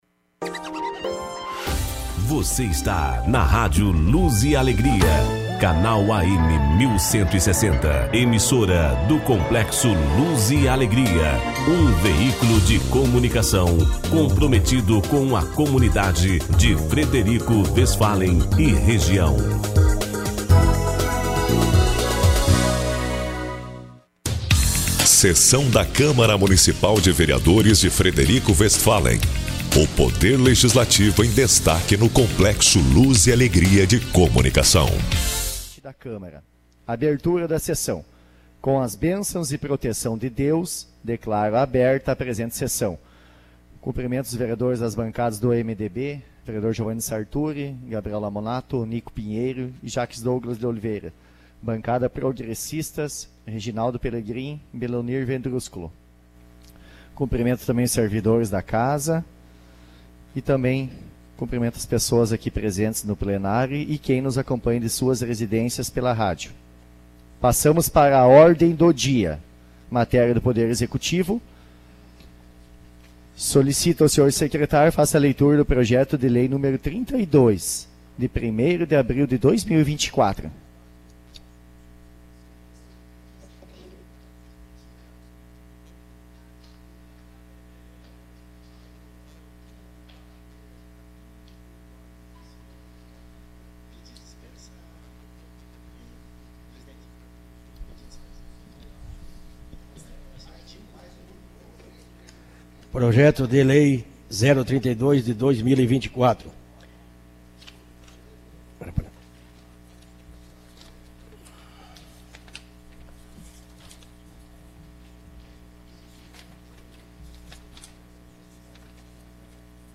Sessão Extraordinária do dia 09 de abril